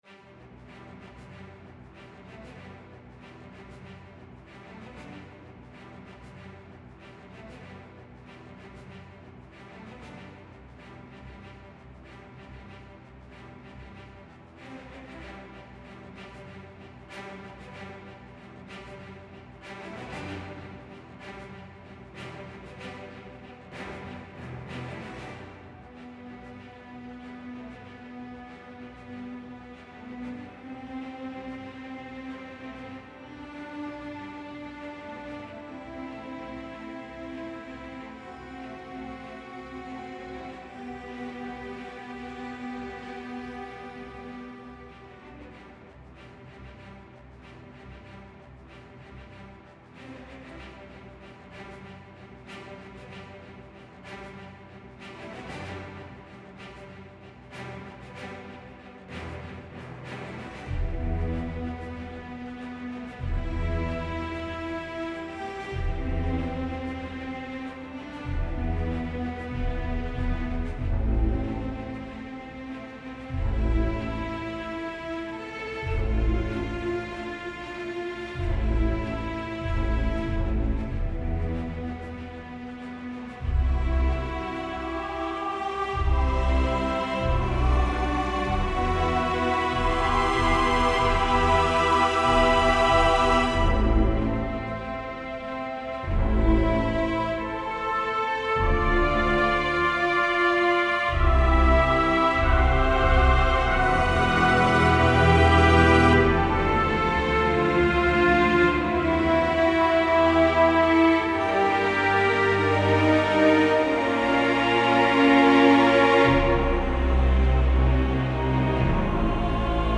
Filmmusik - Feedback und Frage nach Libraries
Idee: Das Böse kommt in die Welt (oder so ähnlich, hab mir einen typischen Hollywoodtrailer vorgestellt).
Intro: deutlich zurückhaltender (Nur Strings) 2. Chorszenen aufgepeppt mit noch zusätzlichen Sounds 3.